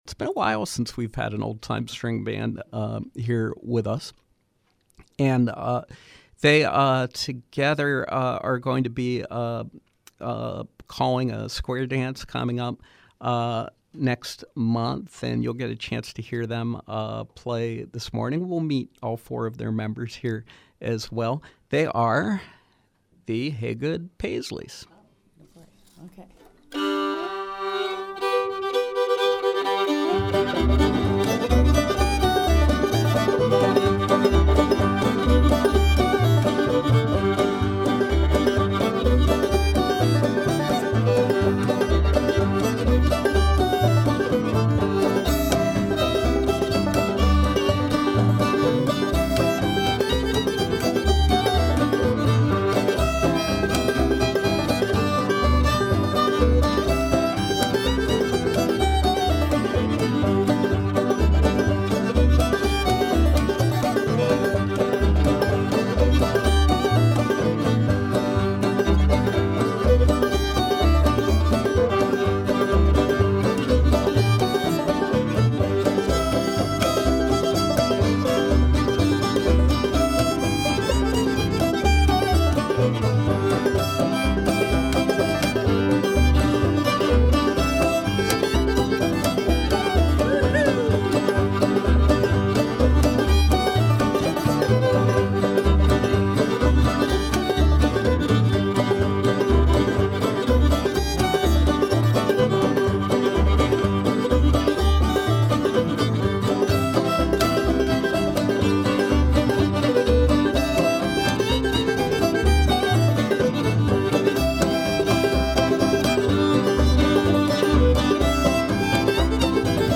old-time string band music.